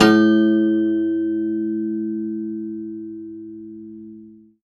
53p-pno05-A0.wav